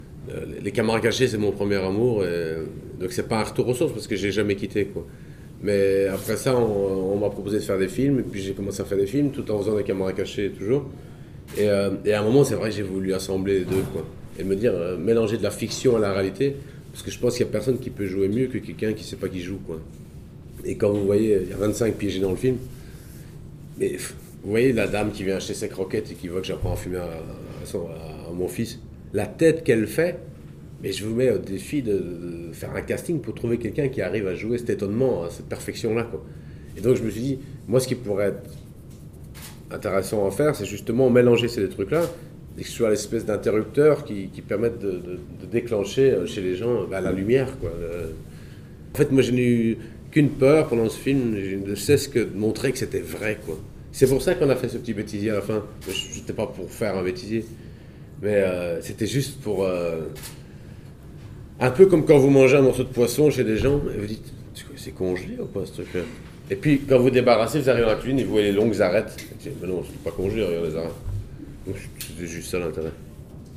Avant première de « Mon Ket » / Rencontre avec François Damiens